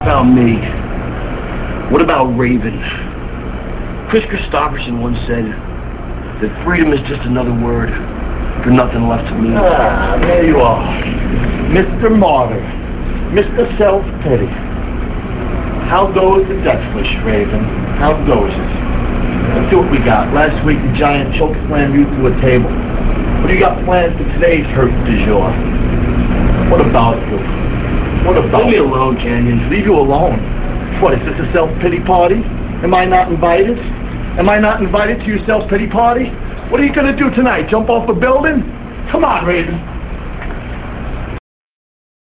- This speech comes from Nitro - [11.2.98] - This comes from a promo video where Raven tells Kanyon to leave him alone, but Kanyon wants to know why he isn't invited to Raven's self pity party. This promo serves to establish Raven's depression angle in WCW.